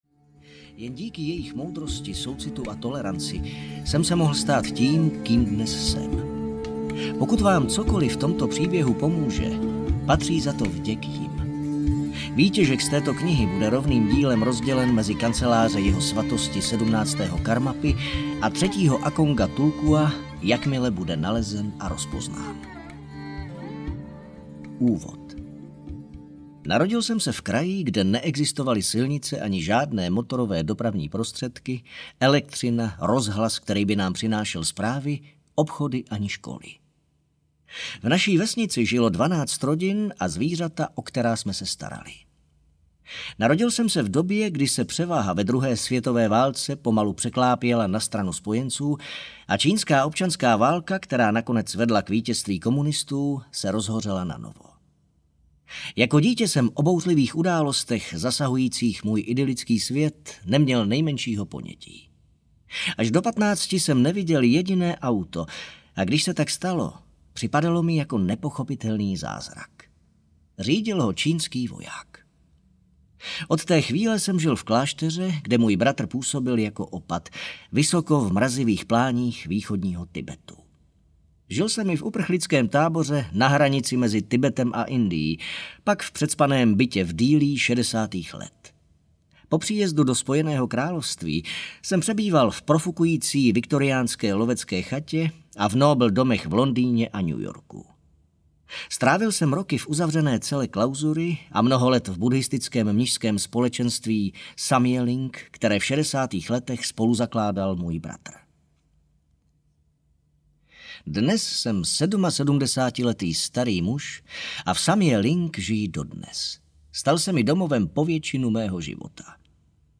Z rebela mnichem audiokniha
Ukázka z knihy